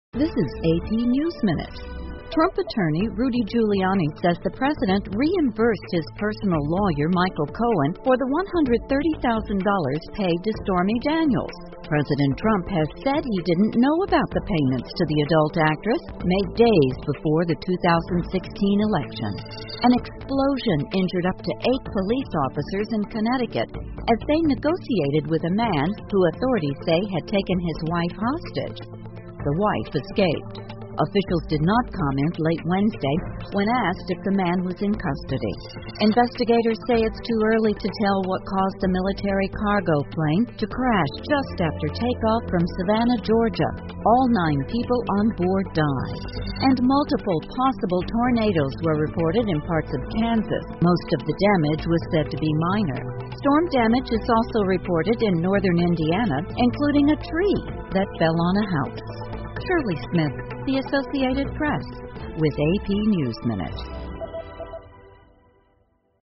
美联社新闻一分钟 AP 萨凡纳一军用飞机坠毁 听力文件下载—在线英语听力室